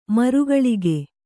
♪ maru gaḷige